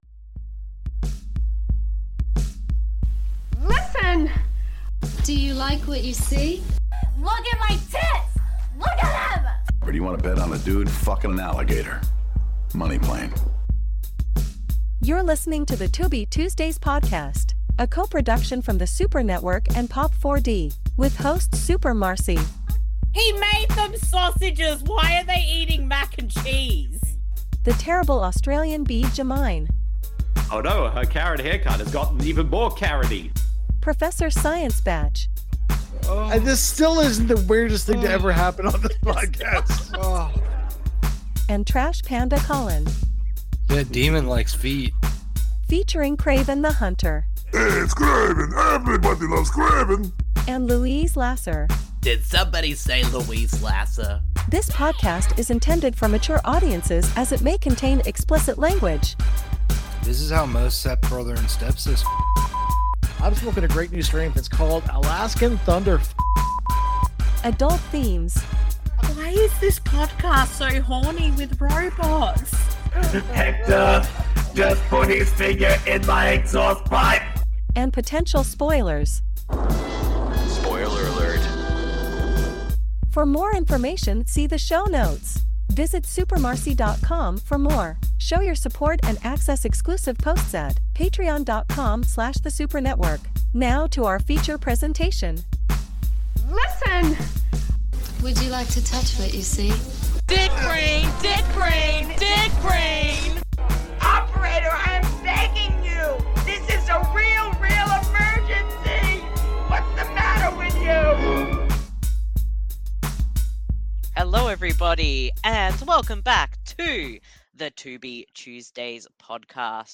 This podcast series is focused on discovering and doing commentaries/watch a longs for films found on the free streaming service Tubi, at TubiTV
Welcome back to The Tubi Tuesdays Podcast, the number one Tubi related podcast that’s hosted by two Australians, one Canadian and one American!